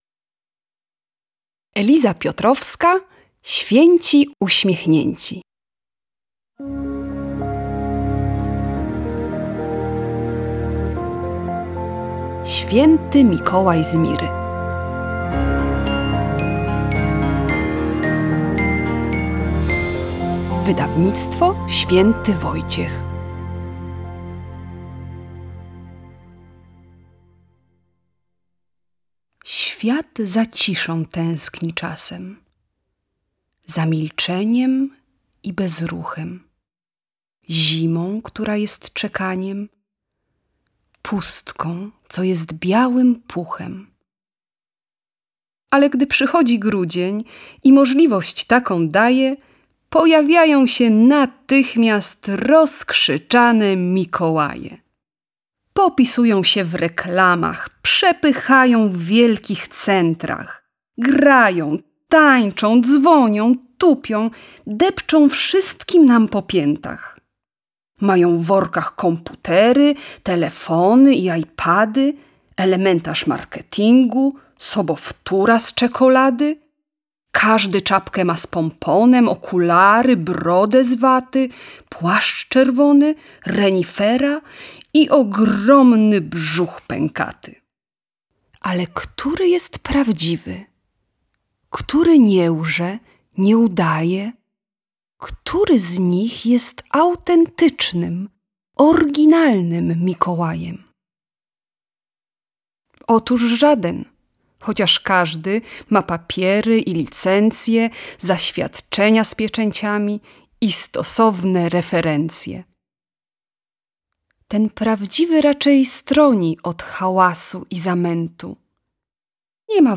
Święty Mikołaj z Miry Audiobook mp3 - Eliza Piotrowska - audiobook